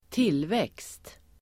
Uttal: [²t'il:vek:st]